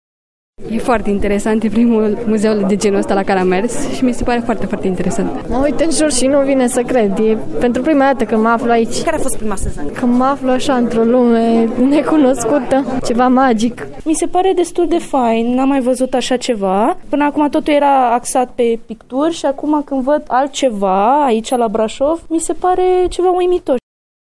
Cei care au reuşit să viziteze încă expoziţia din catacombe s-au declarat impresionați.
VOX-MUZEU-DE-NEW-MEDIA.mp3